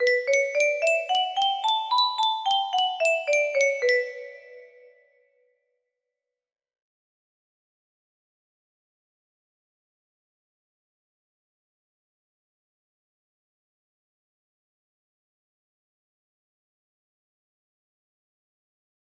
B minor natural scale music box melody